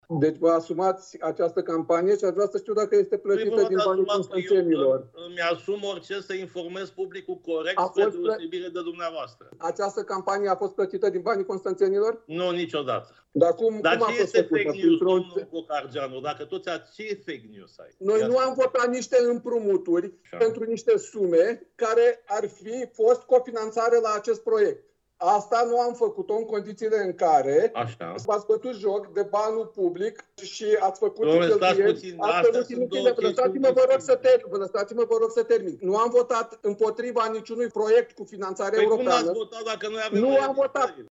Ședința Consiliului Local Constanța a început astăzi cu discuții aprinse între consilierul local USR Florin Cocargeanu și primarul Vergil Chițac.
A urmat un schimb de replici între consilierul USR Florin Cocargeanu și primar: